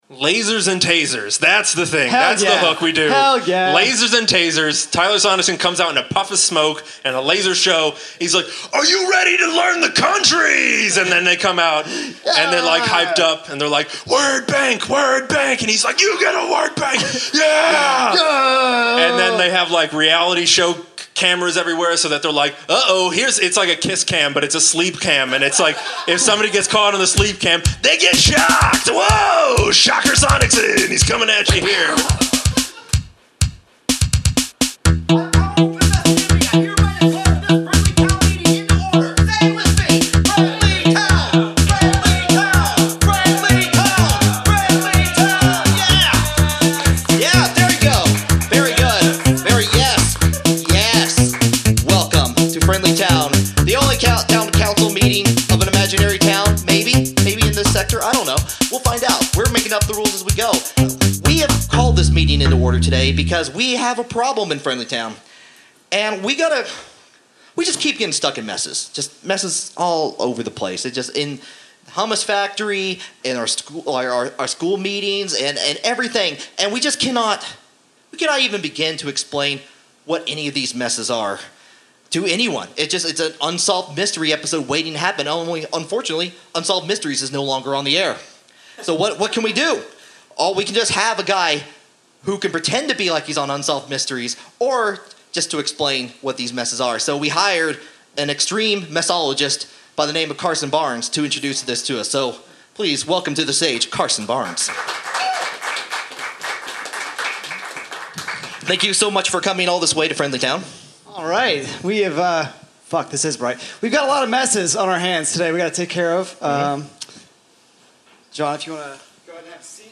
Recorded Live at the Pilot Light February 5, 2017, Knoxville TN Share this: Share on X (Opens in new window) X Share on Facebook (Opens in new window) Facebook Share on Pinterest (Opens in new window) Pinterest Like Loading...